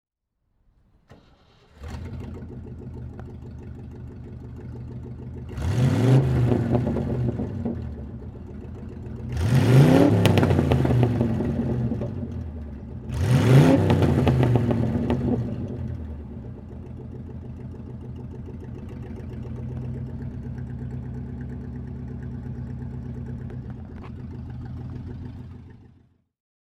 Morgan Plus 8 (1973) - Starten und Leerlauf
Morgan_Plus_8_1973.mp3